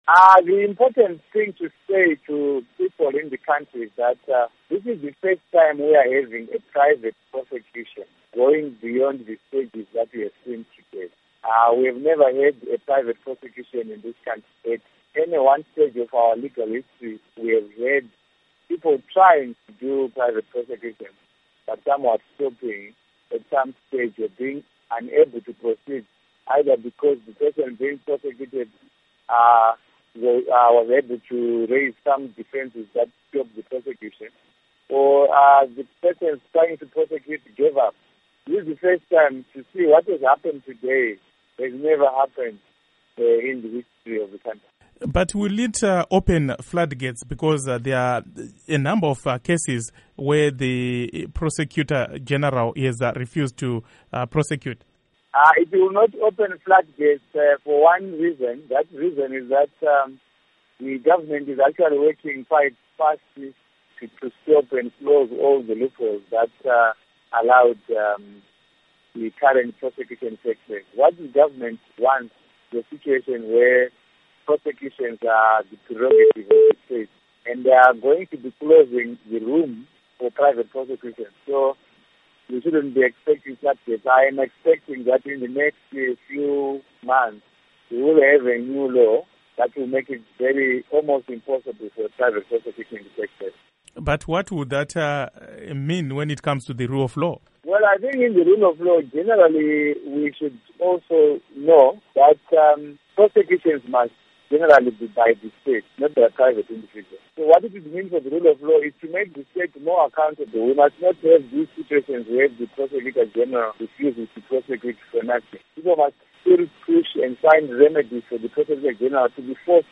Interview With Professor Lovemore Madhuku